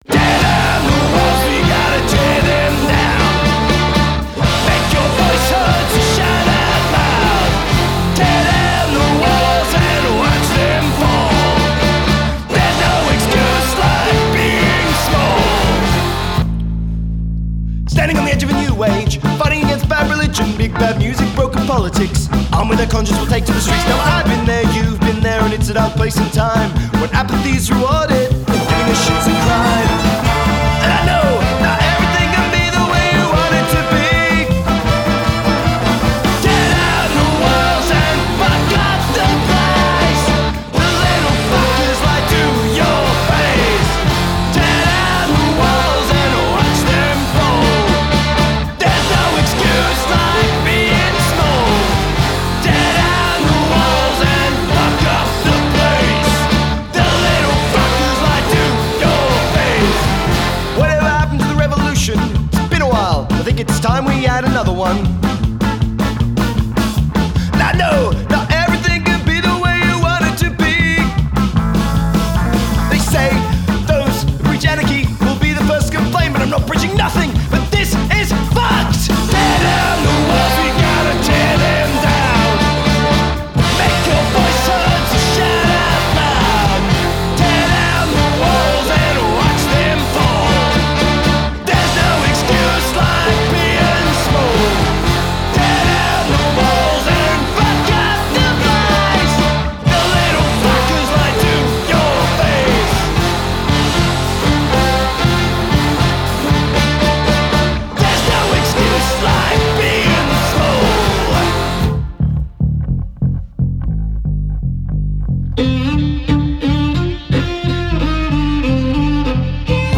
ska band
Clarinet, Vocals
Drums
Bass
Guitar, Vocals
Trombone, Vocals
Saxaphone, Vocals
Violin, Vocals